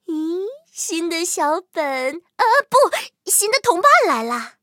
M10狼獾建造完成提醒语音.OGG